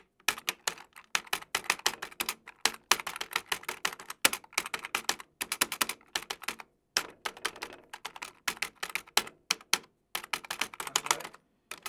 Teclas sueltas de una máquina de escribir electrónica
Sonidos: Oficina